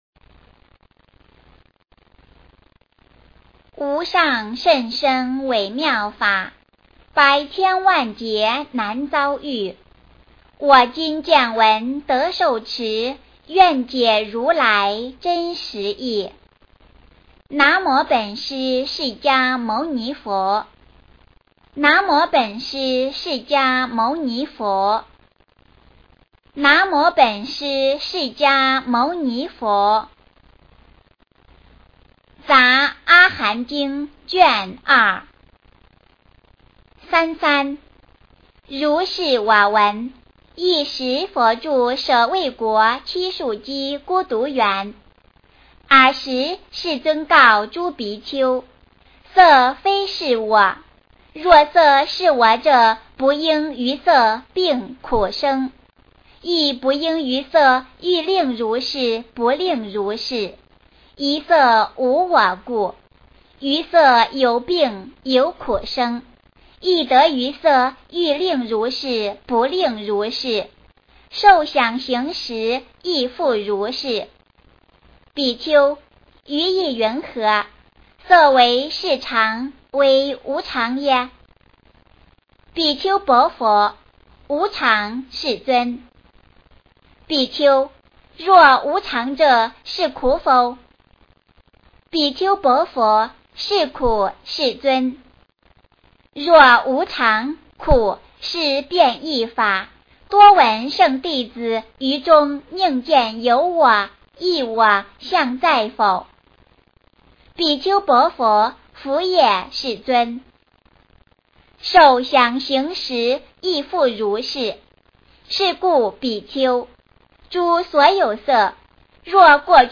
杂阿含经卷二 - 诵经 - 云佛论坛